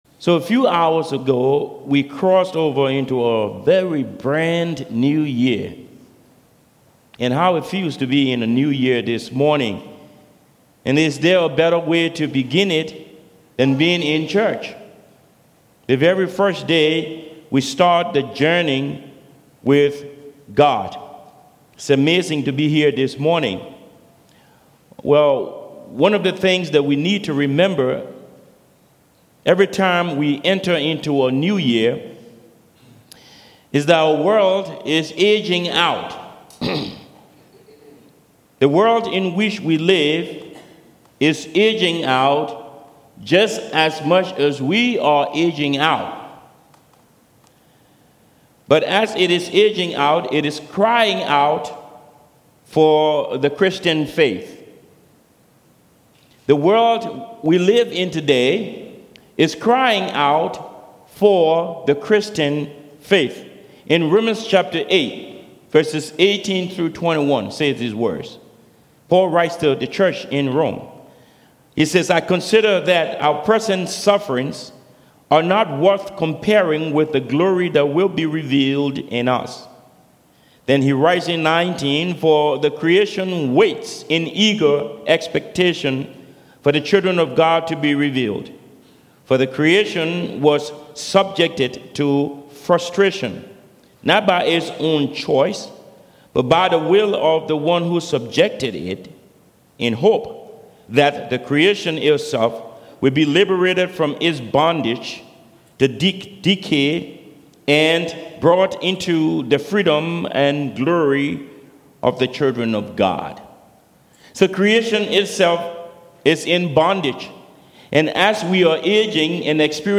Sermon prepared by the Holy Spirit
Service Type: Sermons